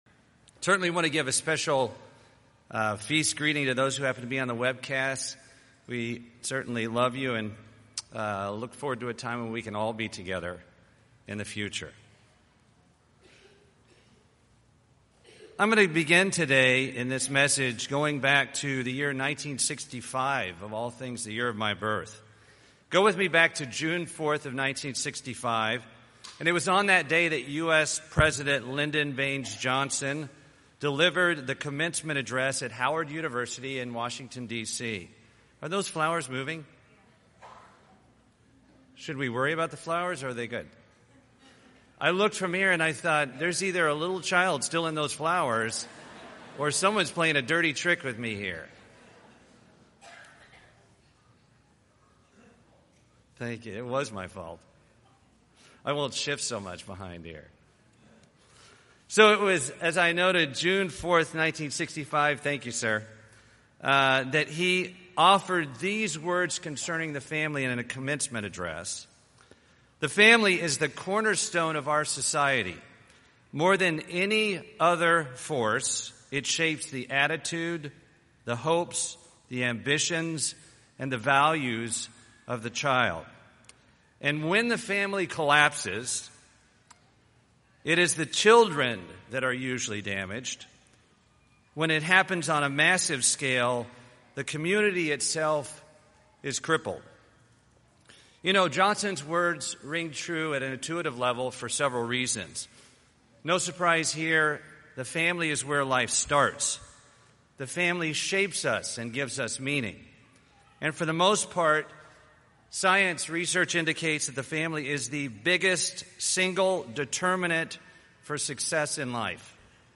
This sermon was given at the Panama City Beach, Florida 2018 Feast site.